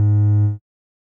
Techmino/media/sample/bass/12.ogg at bc5193f95e89b9c6dfe4a18aee2daa7ea07ff93e
添加三个简单乐器采样包并加载（之后用于替换部分音效）